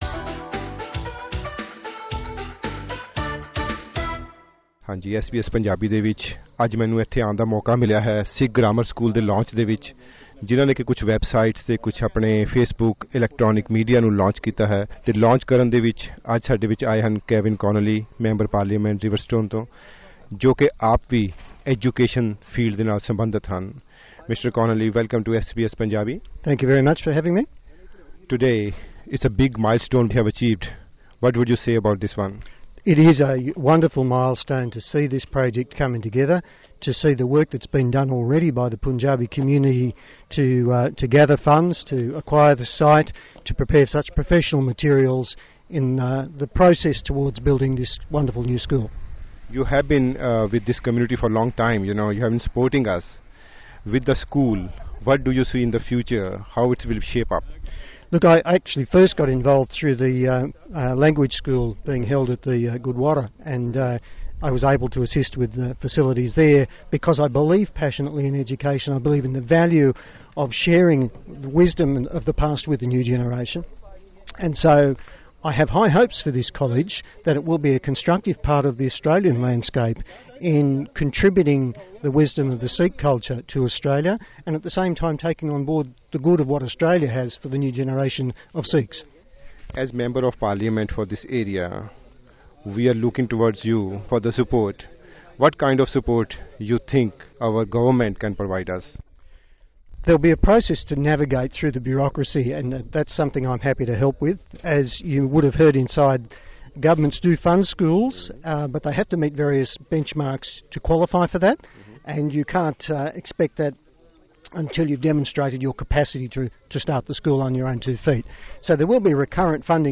Another interview